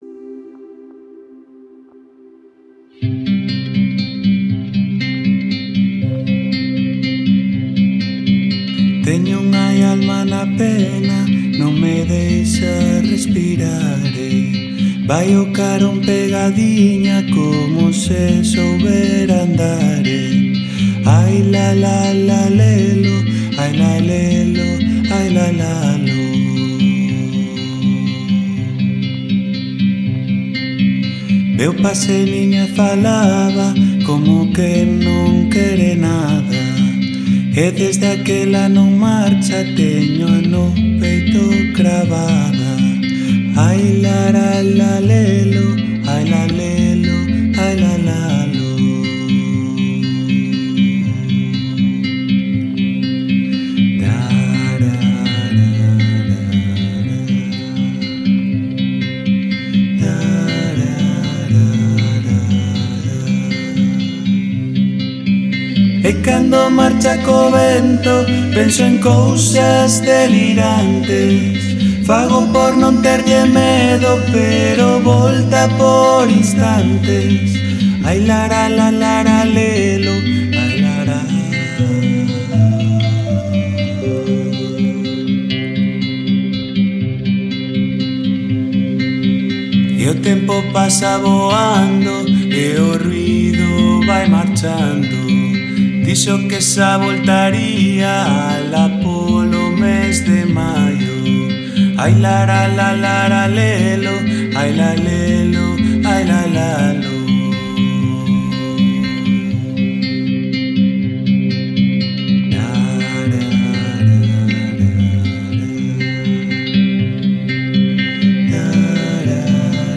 Reimagining Galician Folk: Exploring Contemporary Pop Fusions through Electronic Soundscapes
Väitöskirja syventyy tuotannon yksityiskohtiin, selittäen kuinka elektroniset äänet, kokeelliset tekniikat ja studiotyökalut yhdistettiin ikonisten galicialaisten instrumenttien, kuten tamburiinin, säkkipillin ja klassisen kitaran, kanssa.